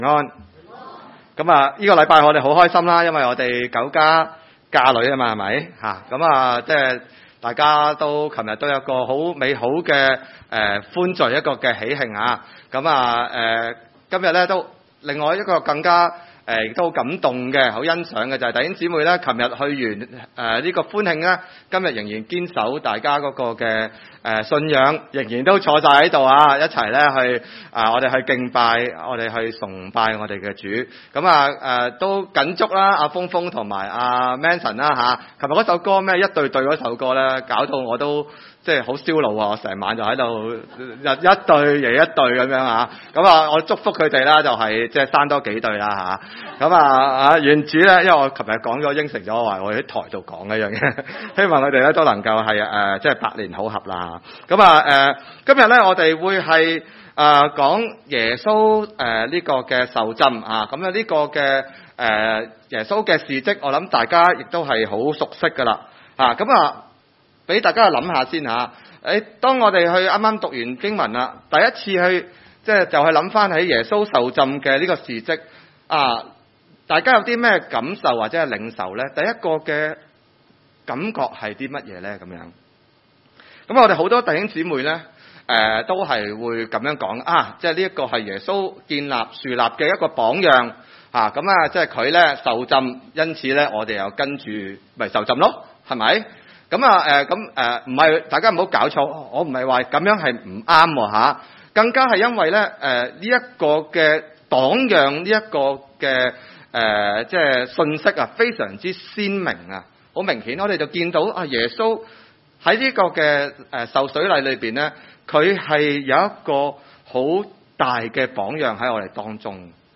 馬太福音3：13-17 崇拜類別: 主日午堂崇拜 13.